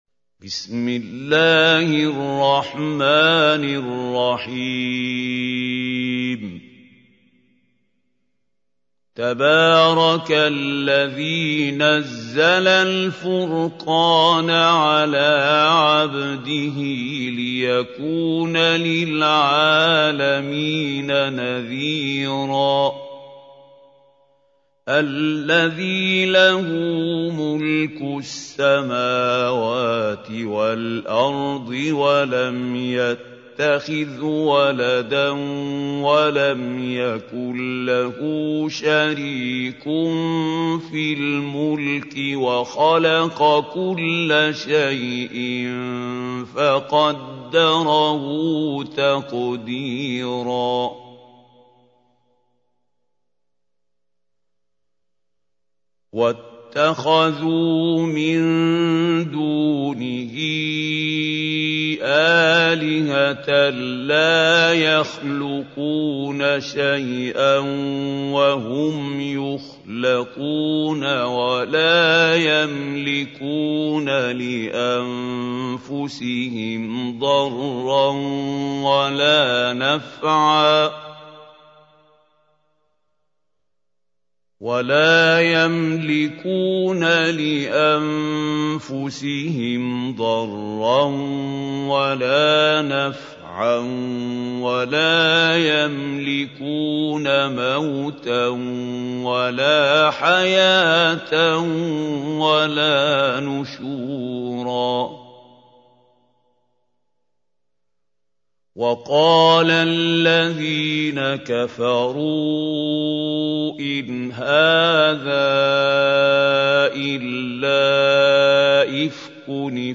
Чтение Корана > ХАЛИЛ ХУСАРИ